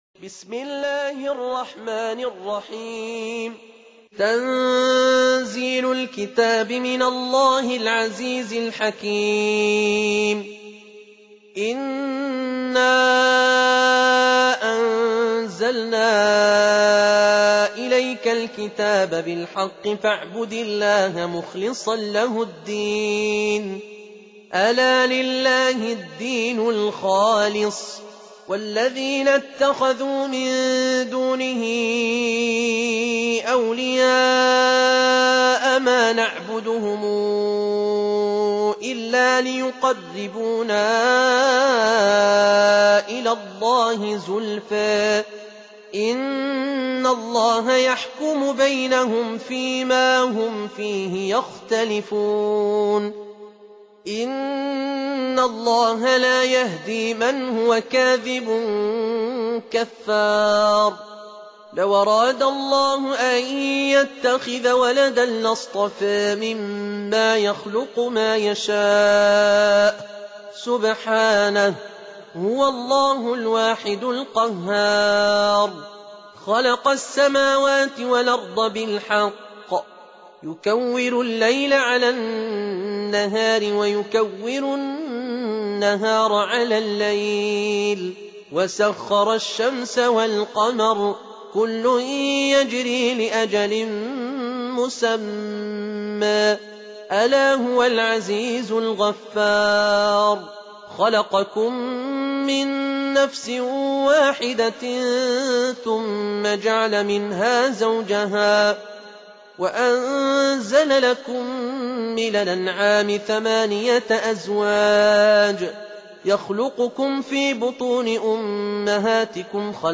39. Surah Az-Zumar سورة الزمر Audio Quran Tarteel Recitation
Surah Sequence تتابع السورة Download Surah حمّل السورة Reciting Murattalah Audio for 39. Surah Az-Zumar سورة الزمر N.B *Surah Includes Al-Basmalah Reciters Sequents تتابع التلاوات Reciters Repeats تكرار التلاوات